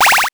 powerup_38.wav